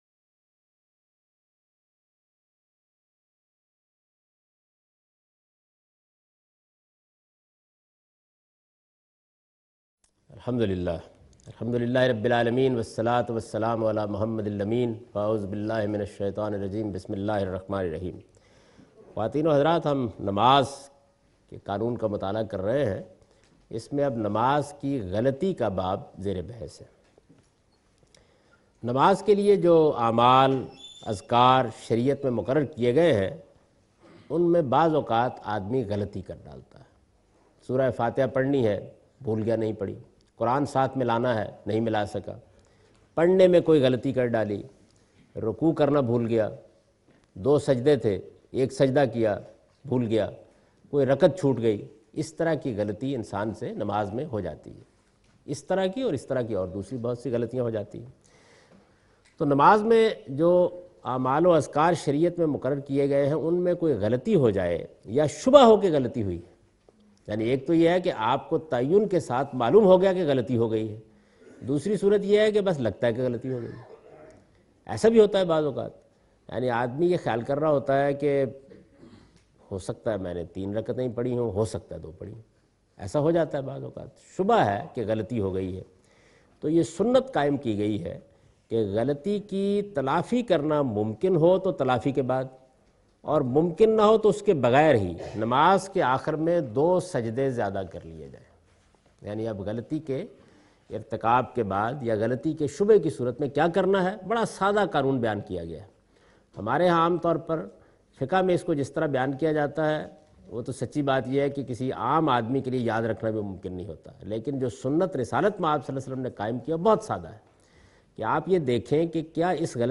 A comprehensive course on Islam, wherein Javed Ahmad Ghamidi teaches his book ‘Meezan’.
In this lecture series he teaches 'The shari'ah of worship rituals'. In this sitting he teaches how to rectify mistakes during prayer.